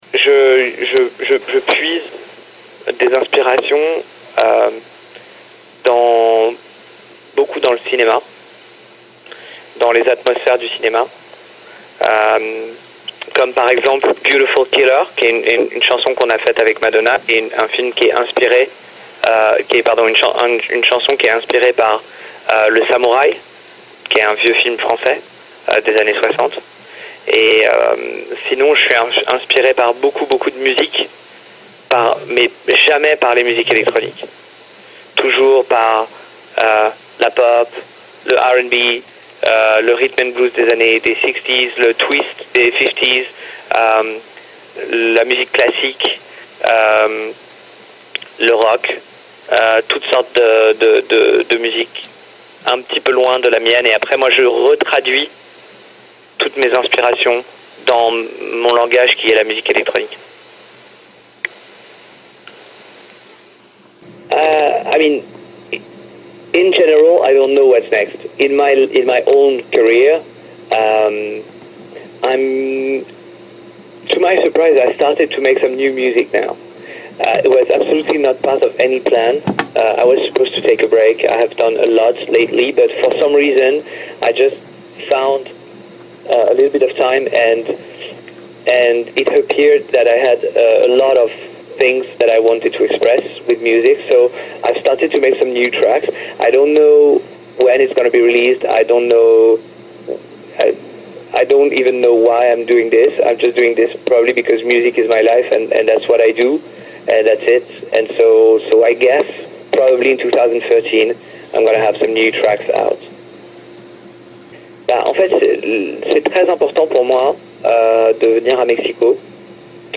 Entrevista exclusiva con Martin Solveig
Entrevista-Martin-Solveig.mp3